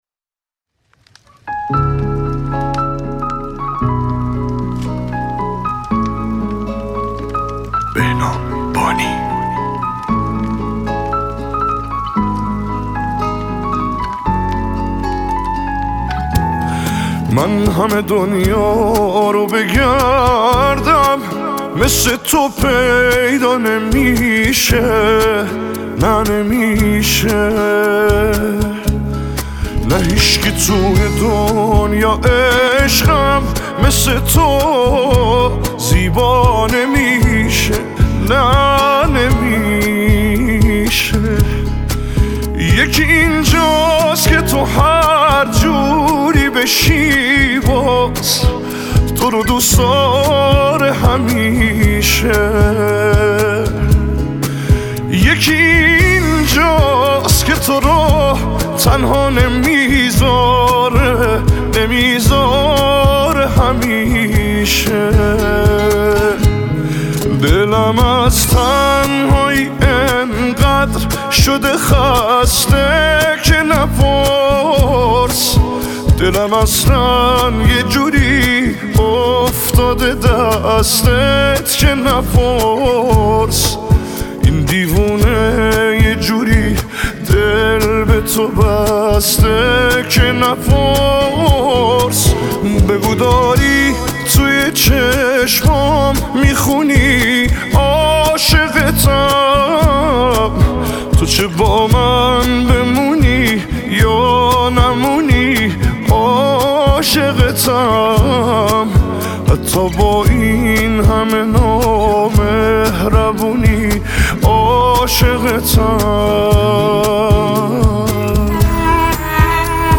نسخه آهسته